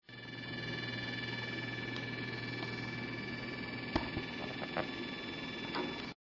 back to mac disasters audio audio: A lab full of first gen intel iMacs were making a sound so high pitched that nothing would record it except an Apple iSight camera. (they had amazing microphones) noisy_imac.mp3 last updated 02/13/2022 at 21:27:19
noisy_imac.mp3